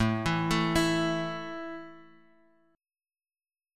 A5 chord {x 0 2 2 x 0} chord